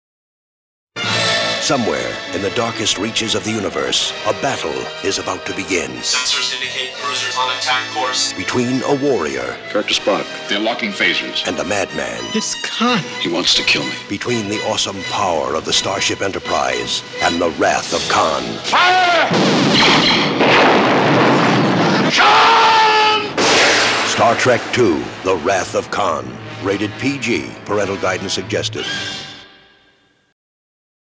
Radio Spots
The spots are very good and capture the action and mood of the movie.